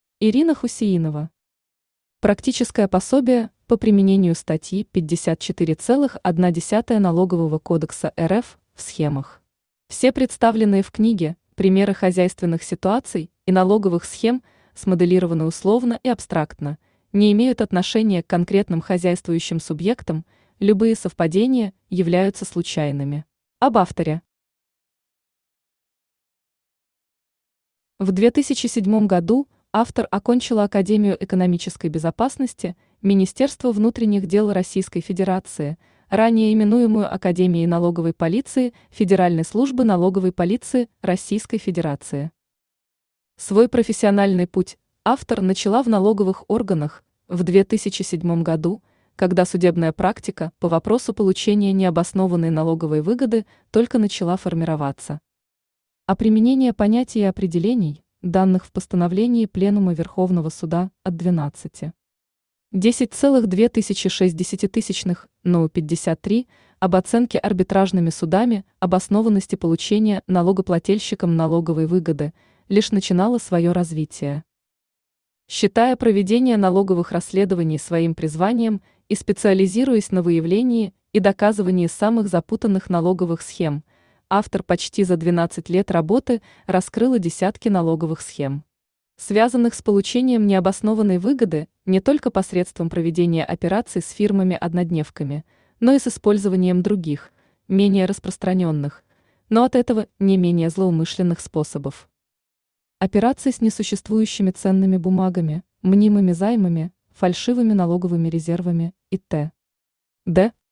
Aудиокнига Практическое пособие по применению статьи 54.1 Налогового кодекса РФ в схемах Автор Ирина Хусяинова Читает аудиокнигу Авточтец ЛитРес.